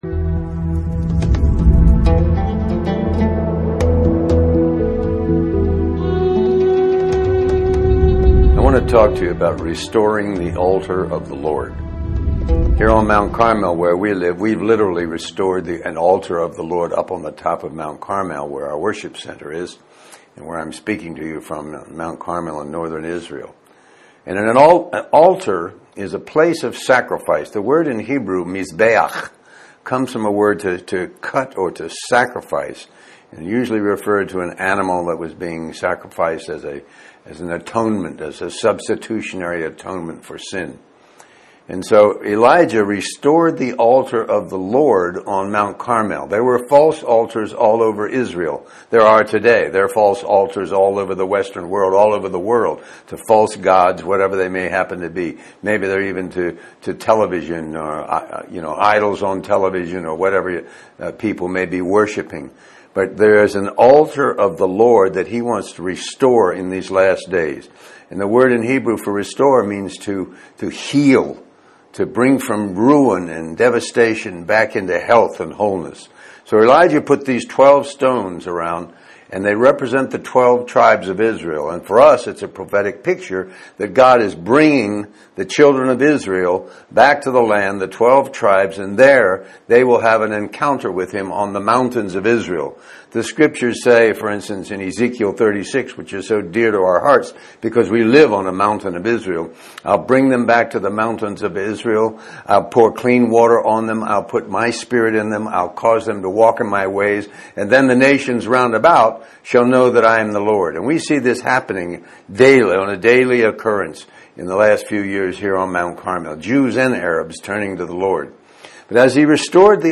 In this sermon, the preacher emphasizes the concept of brokenness as a crucial aspect of one's relationship with God.